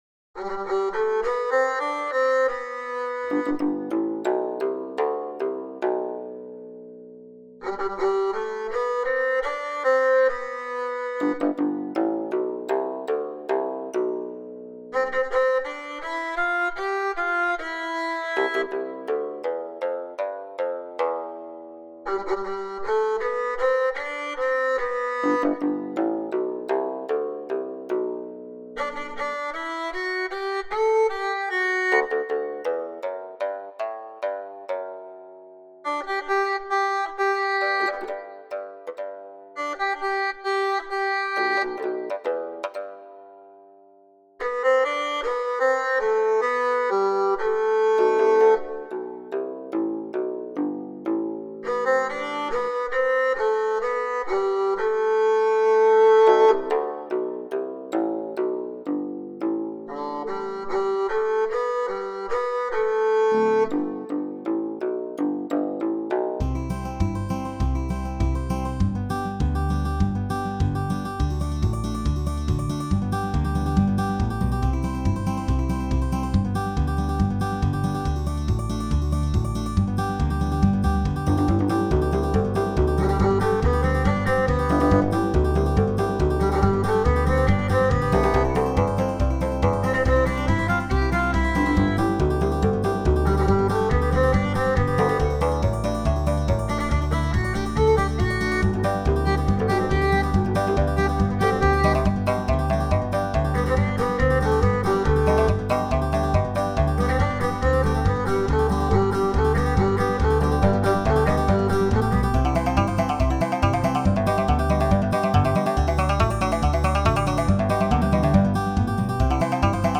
The last two sample file uploads are of 24-bit AIFF GarageBand archive exports to demonstrate their AV Foundation file compatibility.